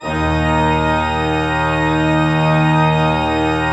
Index of /90_sSampleCDs/Propeller Island - Cathedral Organ/Partition F/PED.V.WERK M